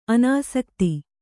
♪ anāsakti